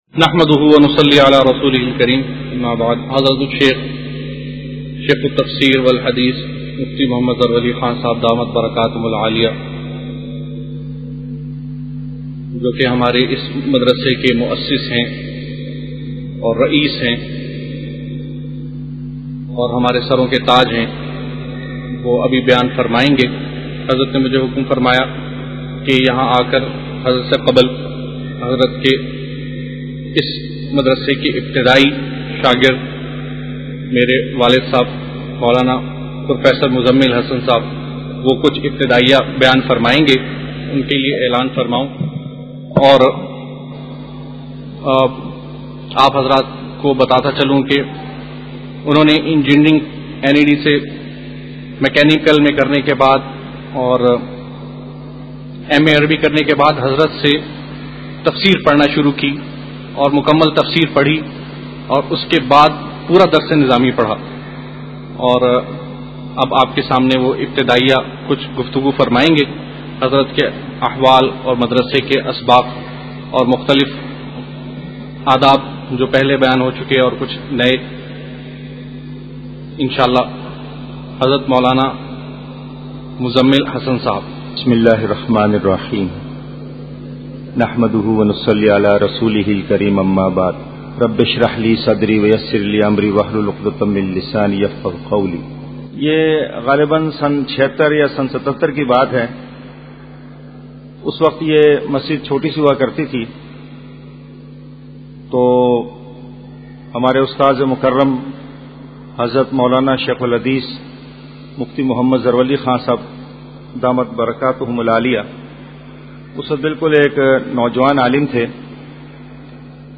افتتاحی بیان